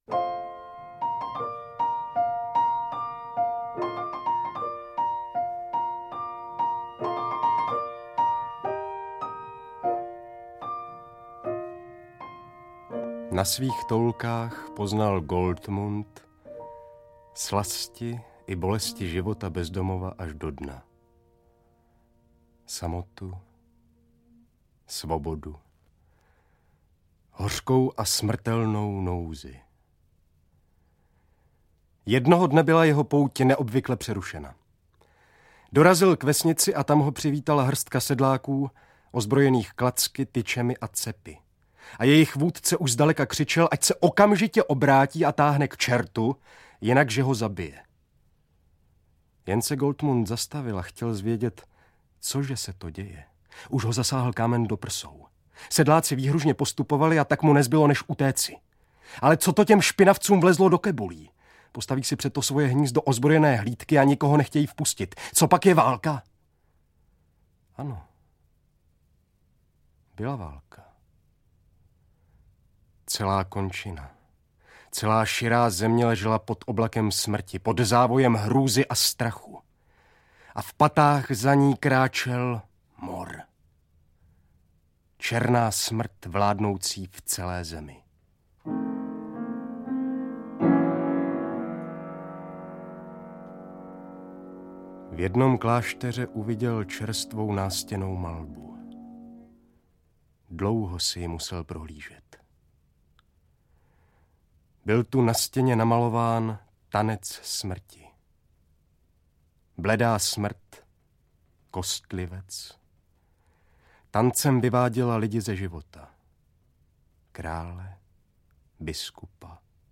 Audiokniha
Celostátní přehlídka uměleckého přednesu Neumannovy Poděbrady vycházela od roku 1974 na deskách Supraphonu.